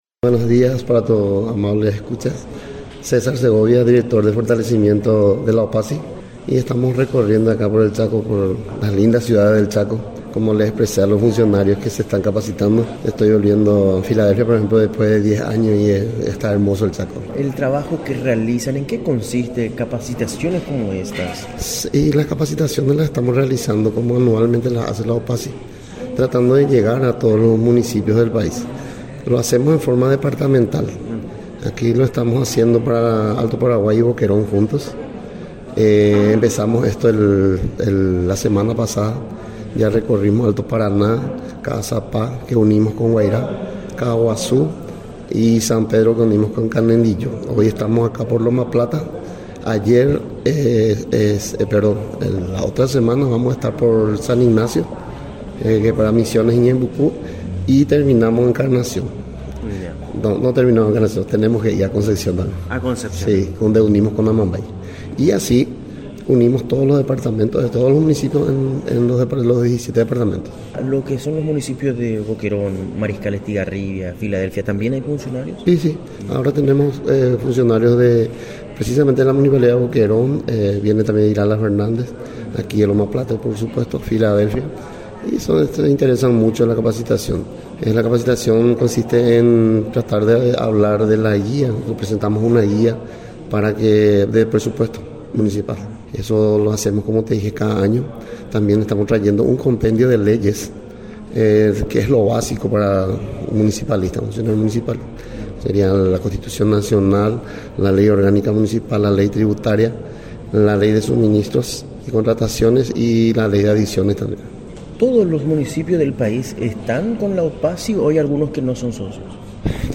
Entrevistas / Matinal 610
Estudio Central, Filadelfia, Dep. Boquerón